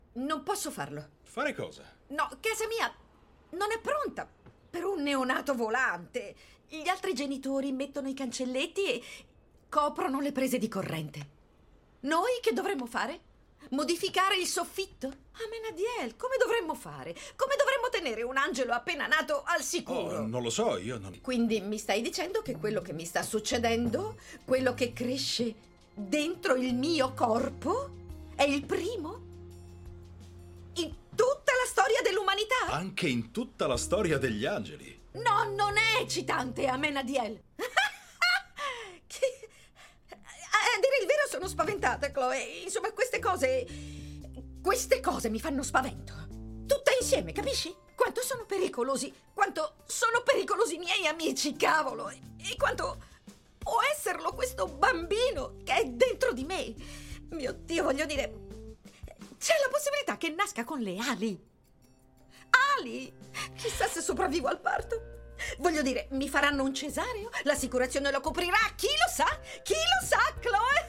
nel telefilm "Lucifer", in cui doppia Rachael Harris.